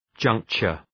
{‘dʒʌŋktʃər}